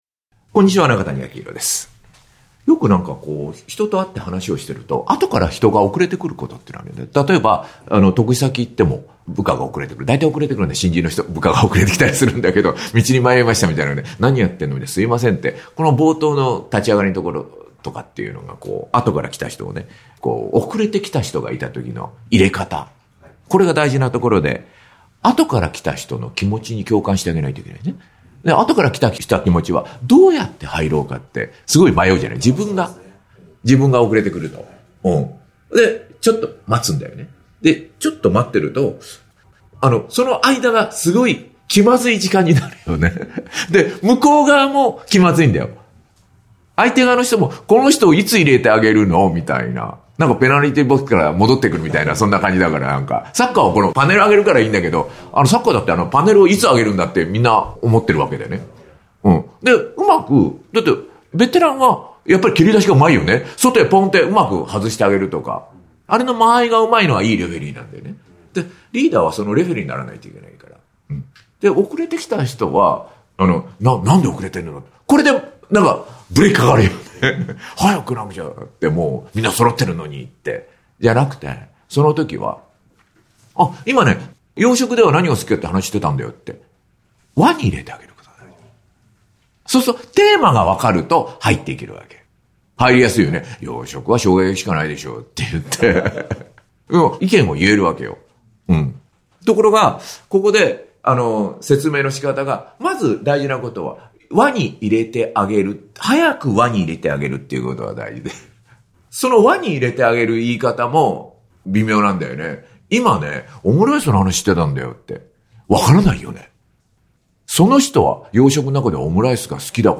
#15 一流の〈会話の“輪”をつくる力〉－リピーターも獲得する－ ↑音声コラムは上記を再生ください↑ ↓【中谷彰宏の〈達人シリーズ〉最新刊】「言いかえの達人」はこちらから 「言い方」を少し変えるだけで、「ビジネス」も「人生」も大きく変わる！